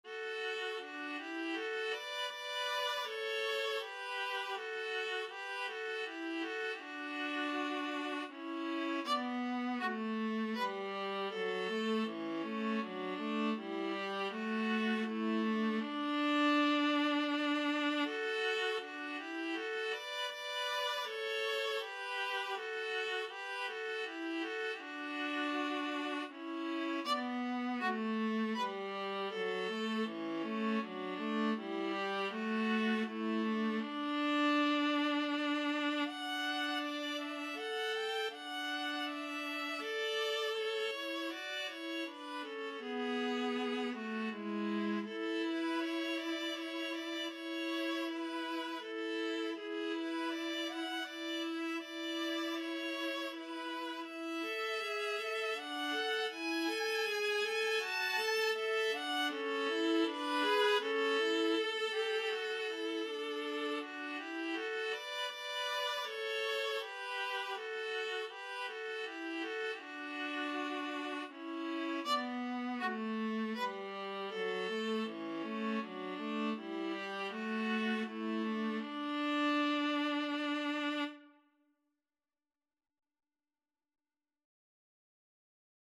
Free Sheet music for Viola Duet
3/4 (View more 3/4 Music)
D major (Sounding Pitch) (View more D major Music for Viola Duet )
Andante
Viola Duet  (View more Easy Viola Duet Music)
Classical (View more Classical Viola Duet Music)